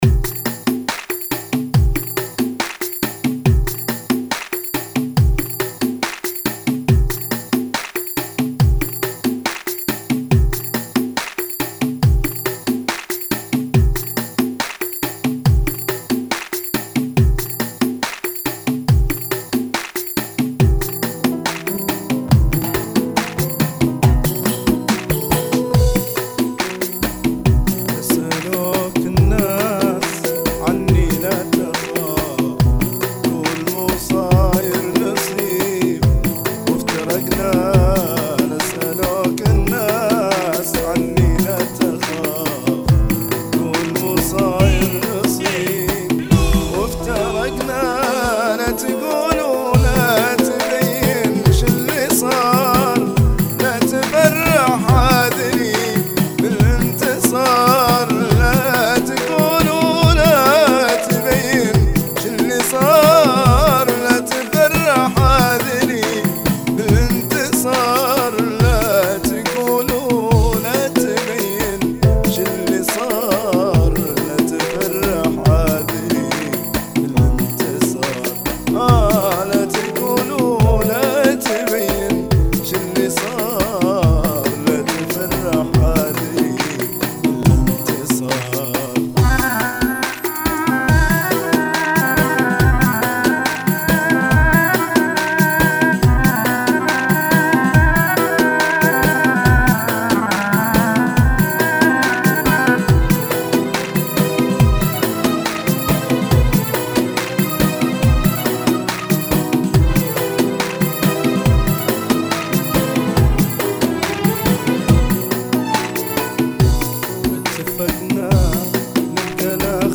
104 Bpm